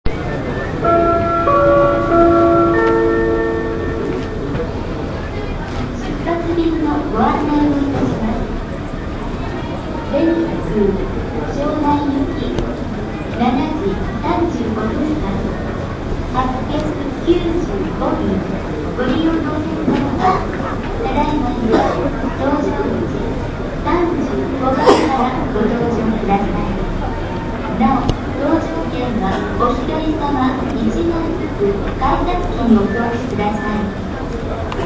羽田空港35番搭乗口
このアナウンスが流れるやいなや、35番搭乗口の前には長い列ができた。